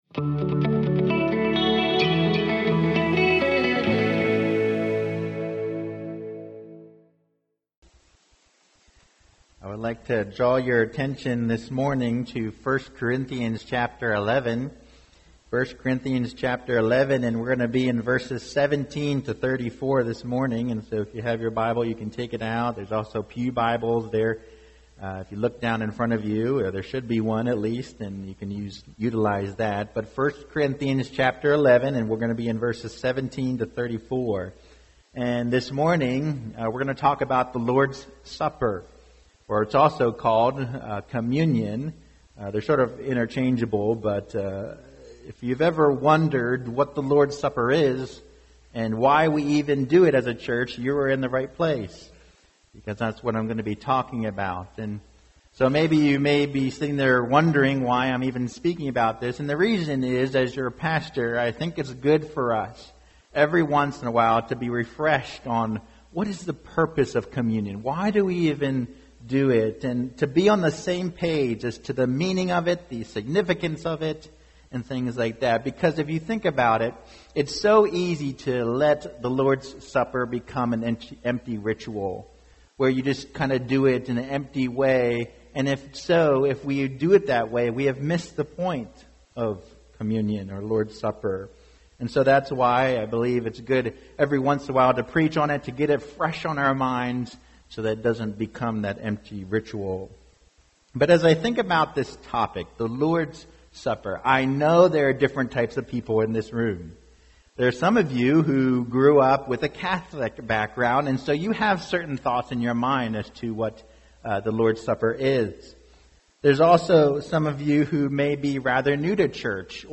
Non-Series Sermon Passage